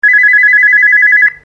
High Pitch.mp3